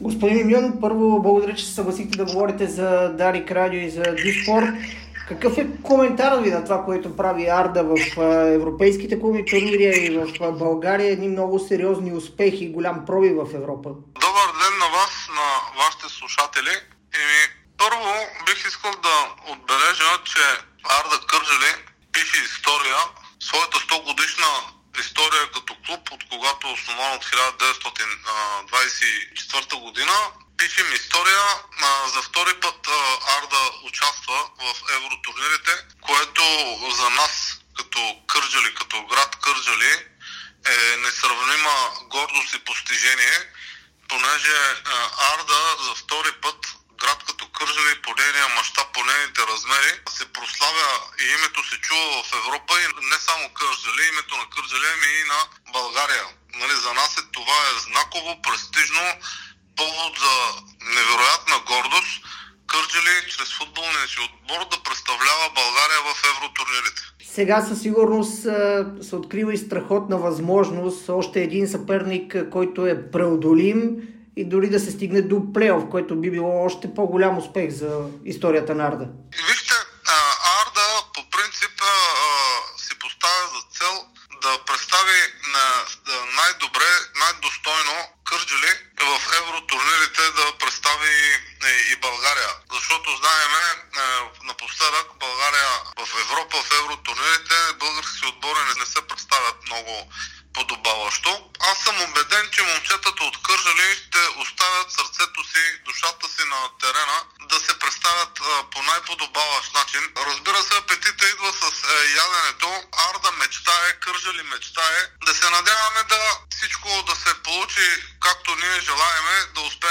Кметът на Кърджали – Ерол Мюмюн, даде ексклузивно интервю за Дарик и dsport. Градоначалникът говори на фона на доброто представяне на местния Арда в евротурнирите.